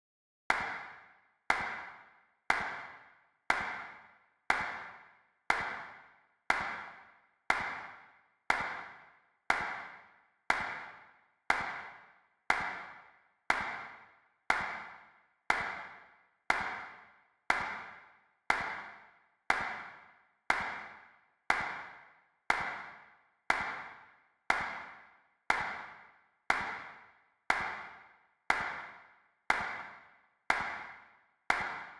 The final sound I’ve made is a clap itself.
I transposed the the clap down slightly and then added reverb.
clap.wav